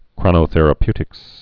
(krŏnō-thĕrə-pytĭks, krōnə-)